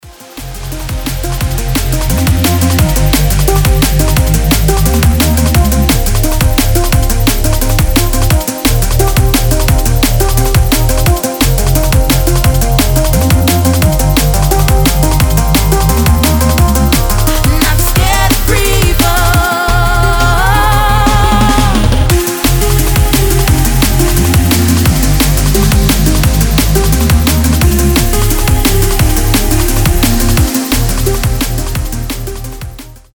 драм энд бейс